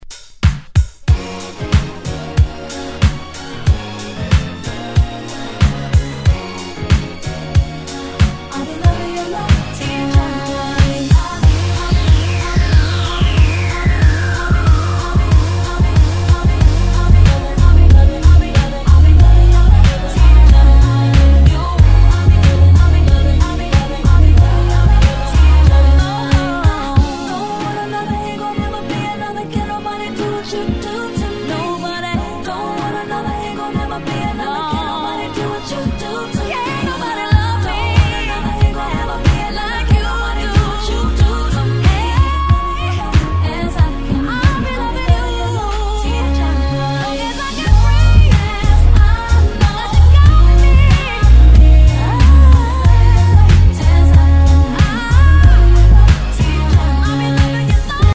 Tag       R&B R&B